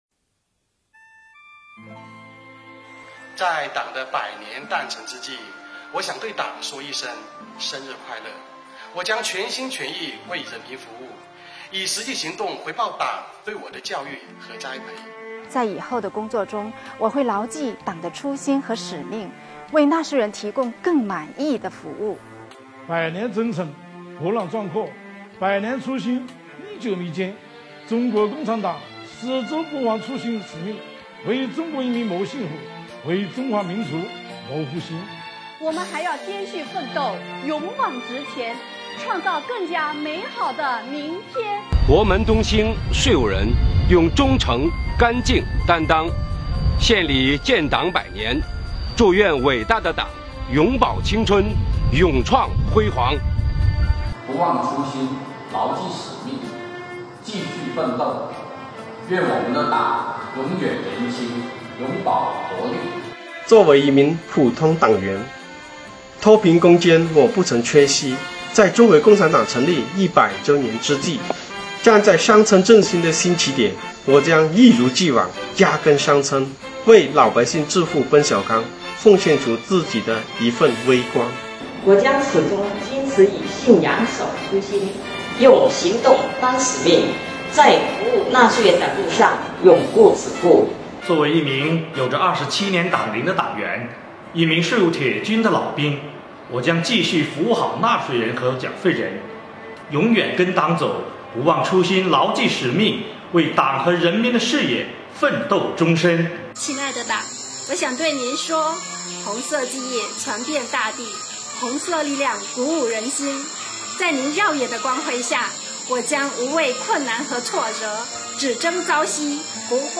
广西税务的党员同志对党的感恩和祝福！